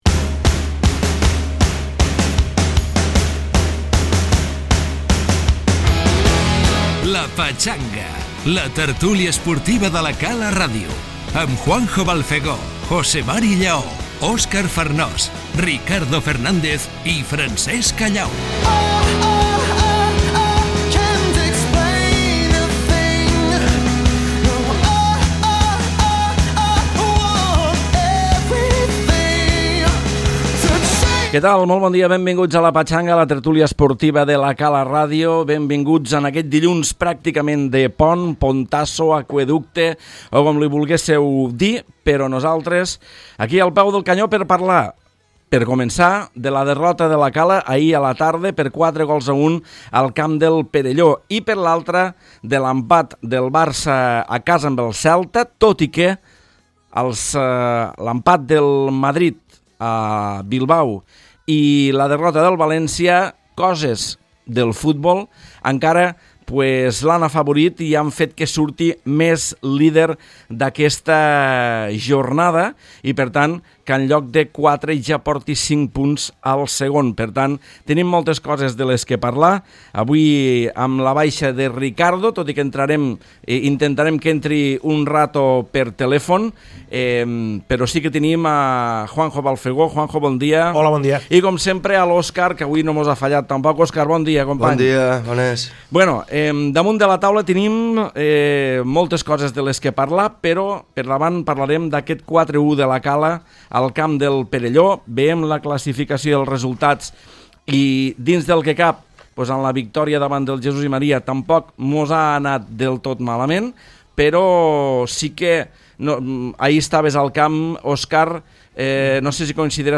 La tertúlia futbolística dels dilluns s’ha centrat en la derrota de La Cala contra el Perelló i per la polèmica a les xarxes socials. També hem parlat de l’actualitat del Barça i el Madrid.